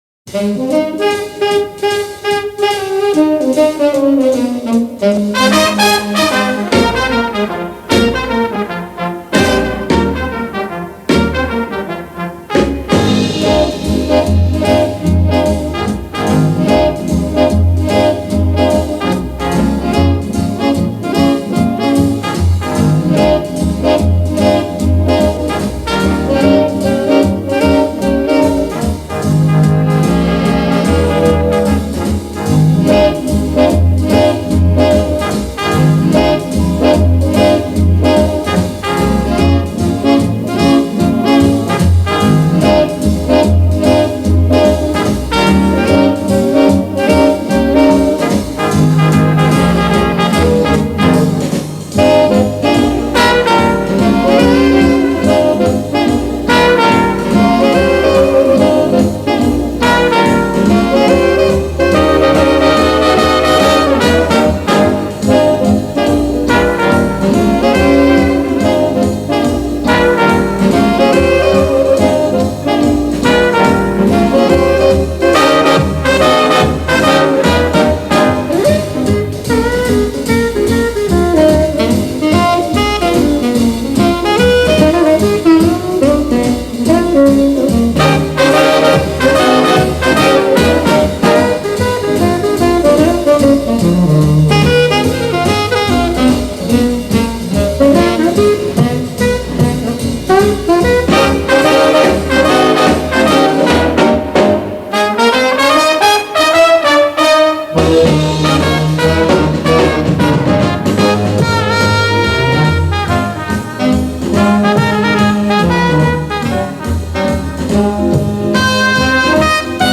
Track7_Jazz_Instrumental.mp3